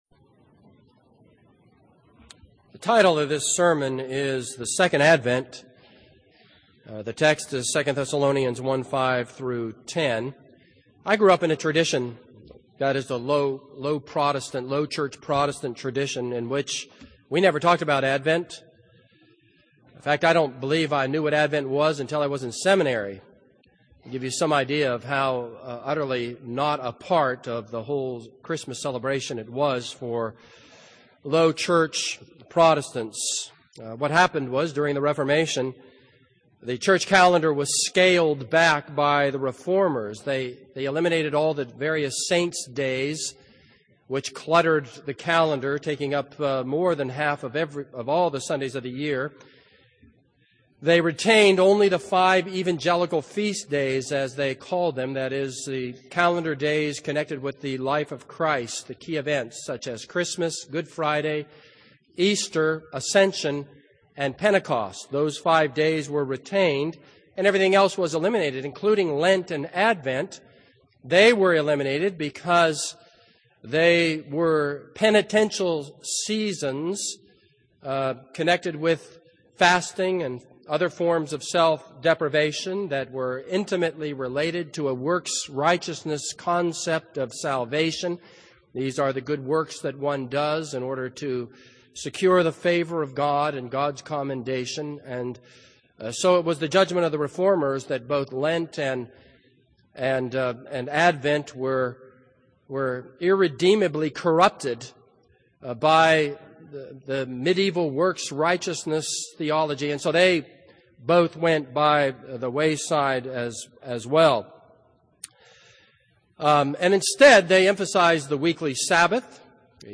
This is a sermon on 2 Thessalonians 1:5-10.